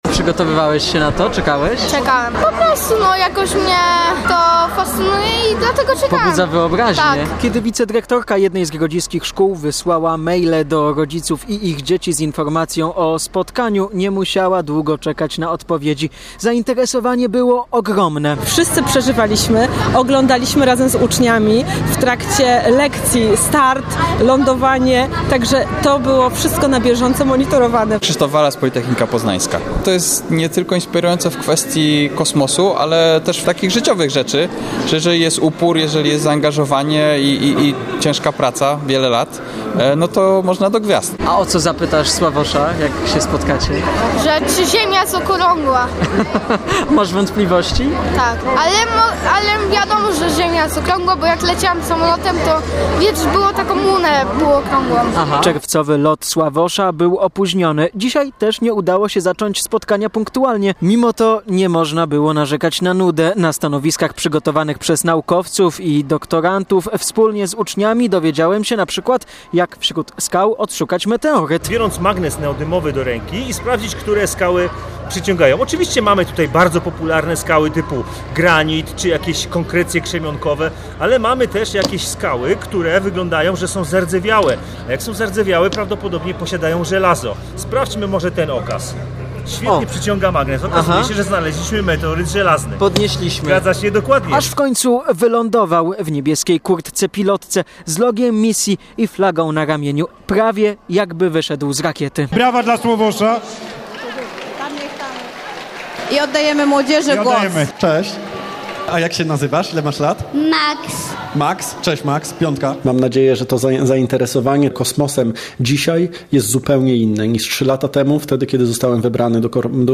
W najbliższych dniach zapadnie decyzja o przyszłości polskiej obecności w kosmosie – powiedział w rozmowie z Radiem Poznań Sławosz Uznański-Wiśniewski. Astronauta spotkał się dziś (17.11) z uczniami podczas wizyty na kampusie Politechniki Poznańskiej w Kąkolewie, niedaleko Grodziska Wielkopolskiego.